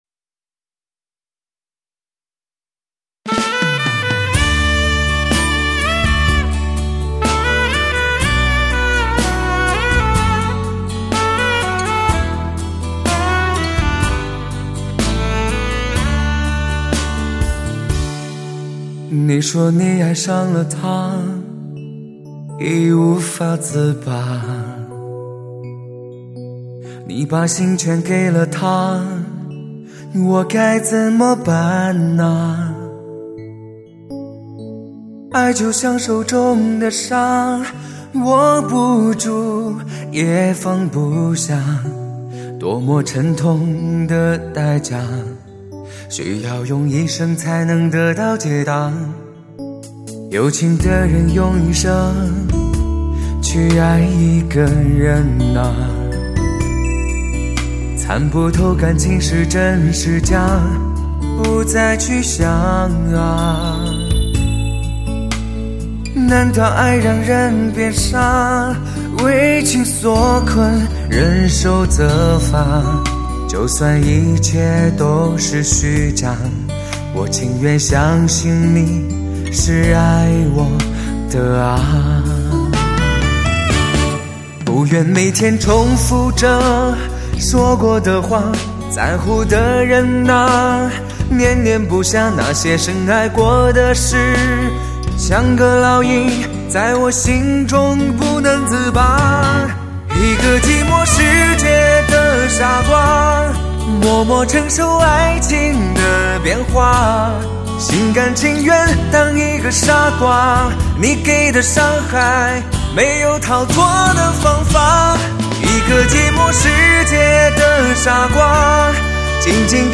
多了几分醇厚！实乃男声HI-FI碟中的典范！